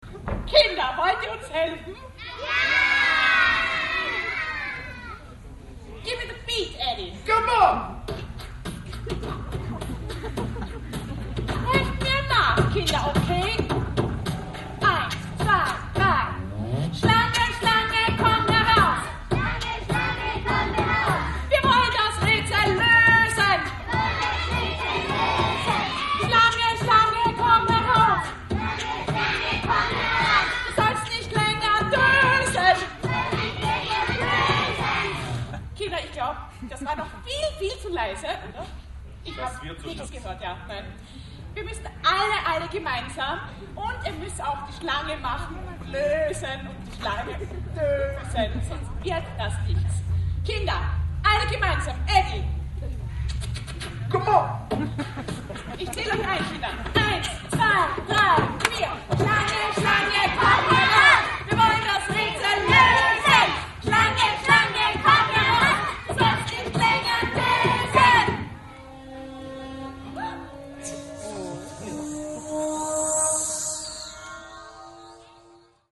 Schlangen-Lied live (5.9.)
schlange_live.mp3